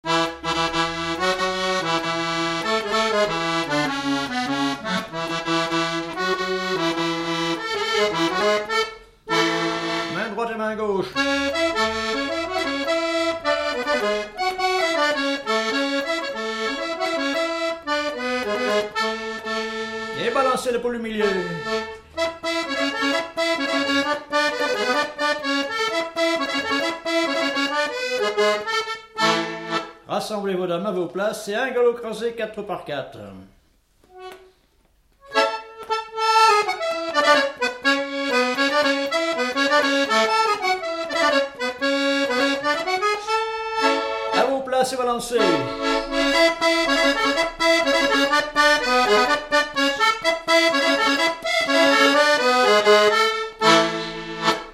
Résumé instrumental
danse : quadrille : poule
Pièce musicale inédite